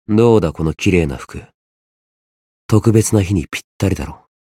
觉醒语音 どうだこの綺麗な服。